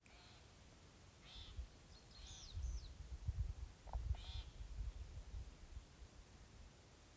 Lielā čakste, Lanius excubitor
Administratīvā teritorijaAlūksnes novads
StatussUztraukuma uzvedība vai saucieni (U)